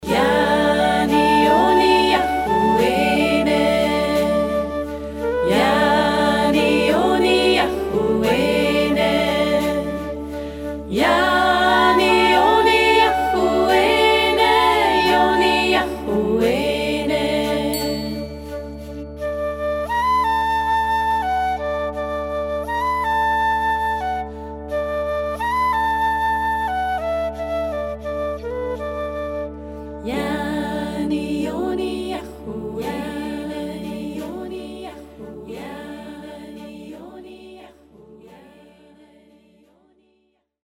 Geburtslied
88 bpm in D